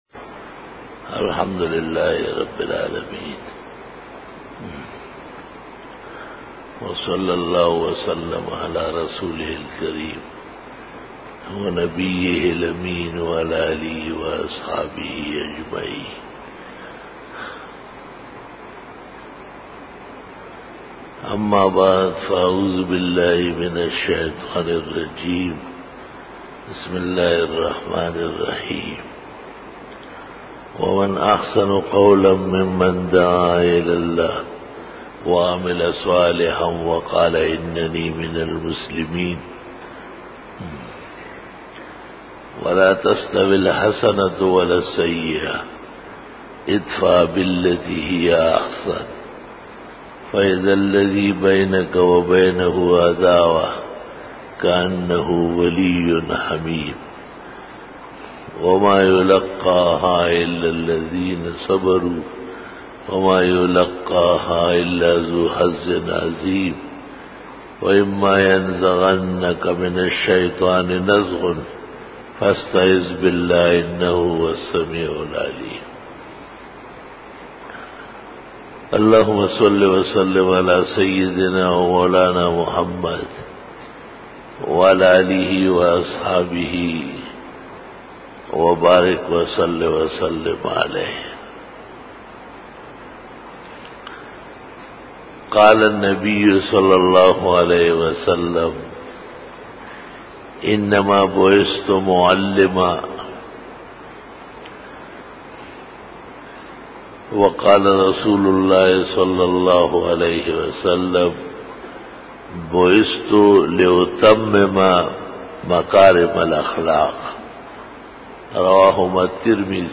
بیان جمعۃ المبارک